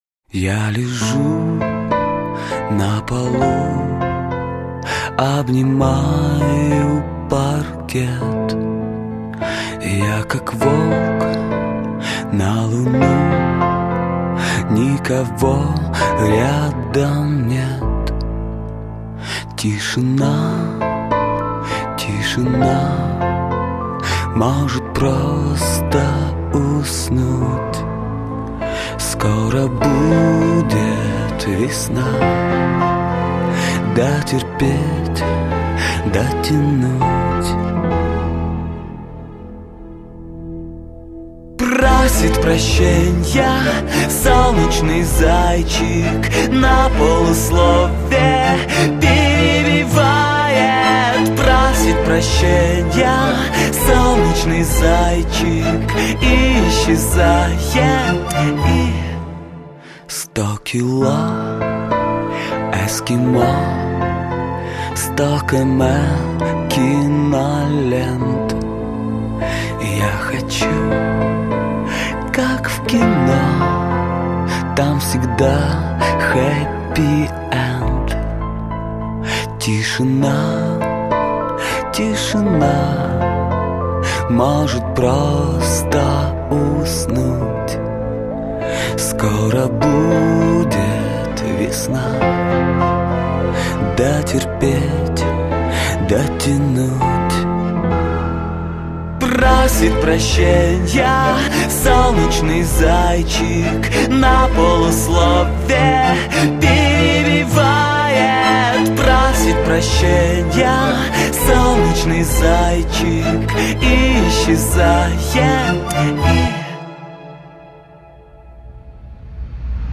Русский рок Поп Рок